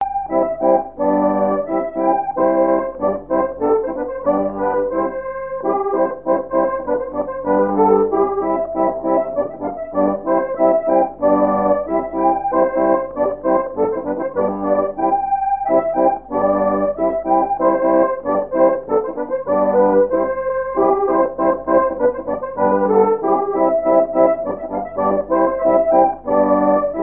Chants brefs - A danser
danse : scottich sept pas
Pièce musicale inédite